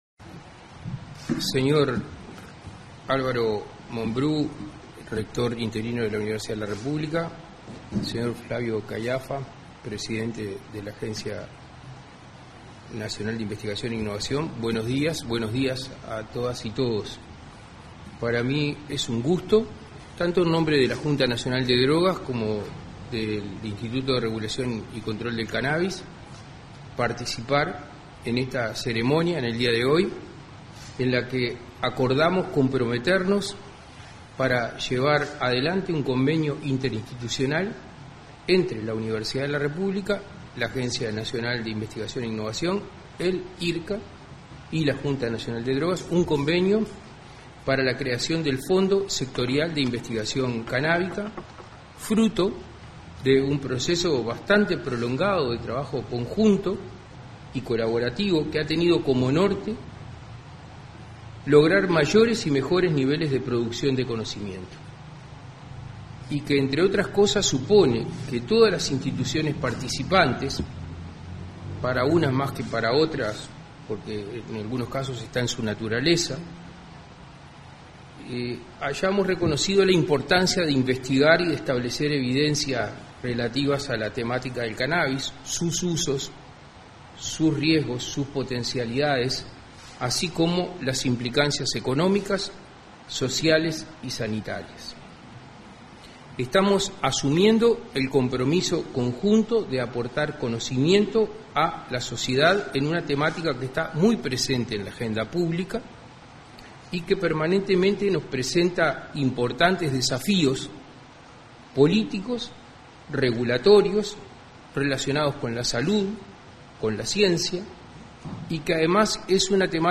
Ceremonia de firma de convenio para crear el Fondo Sectorial de Investigación Cannábica
En el marco de la suscripción de un convenio para crear el Fondo Sectorial de Investigación Cannábica, este 10 de febrero, se expresaron el rector interino de la Universidad de la República, Álvaro Mombrú; el secretario nacional de la Junta Nacional de Drogas, Daniel Radío, y el presidente de la Agencia Nacional de Investigación e Innovación, Flavio Caiafa.
conferencia.mp3